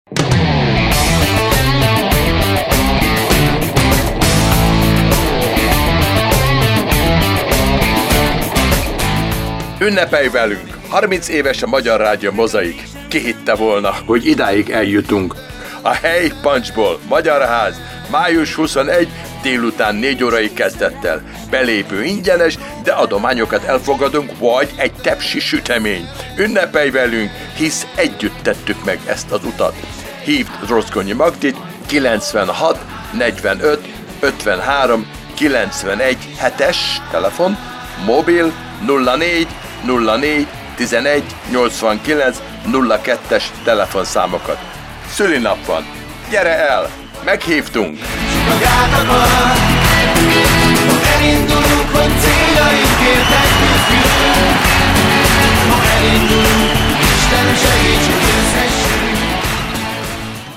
Unnepelj-velunk-Radio-hirdetes-N.-II.mp3